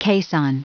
Prononciation du mot caisson en anglais (fichier audio)
Prononciation du mot : caisson